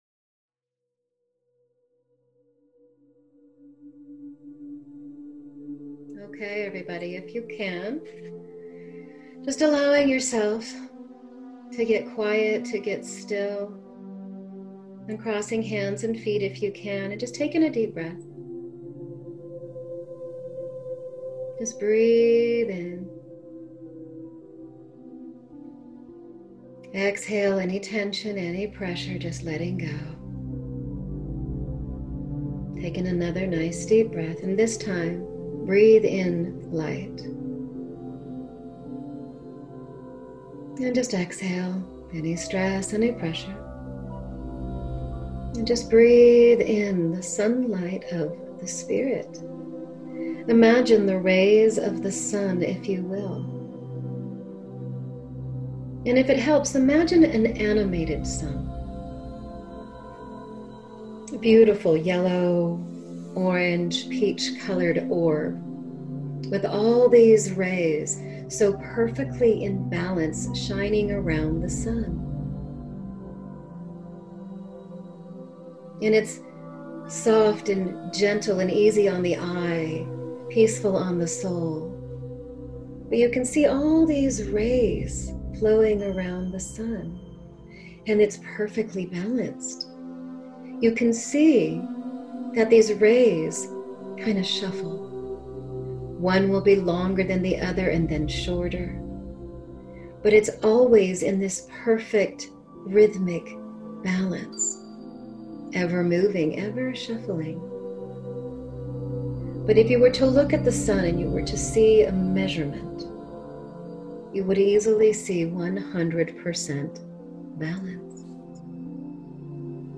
Guided Meditation in a soothing voice. Energy healing, energy clearing, Divine Light, meditation, spirituality.
Balance+Meditation.mp3